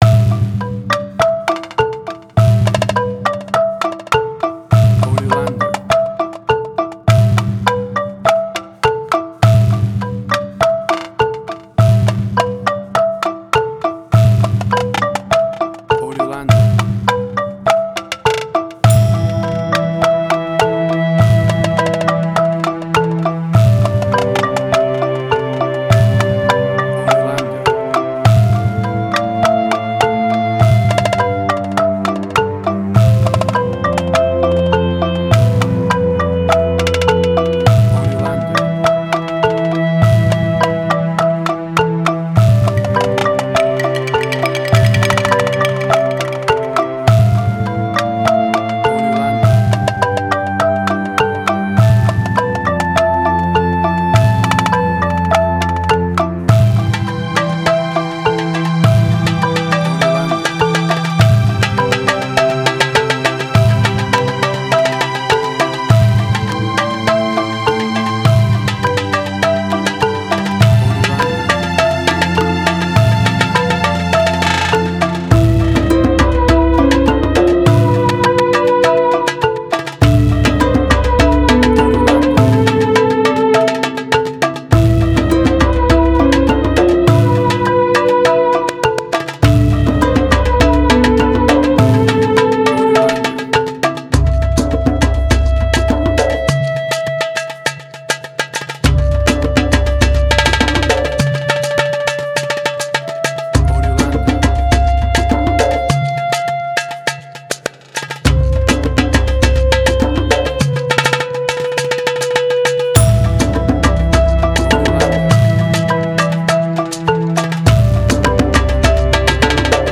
Middle Eastern Fusion.
Tempo (BPM): 102